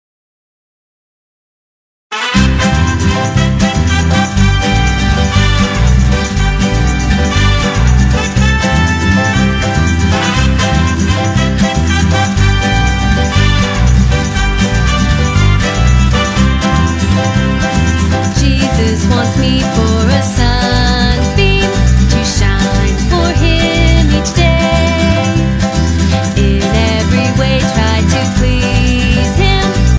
Children's Christian Song Lyrics